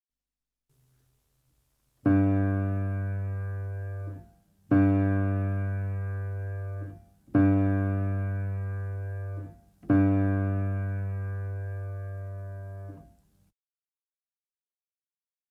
59 Tuning Note - G-String (Cello)